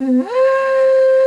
D3FLUTE83#10.wav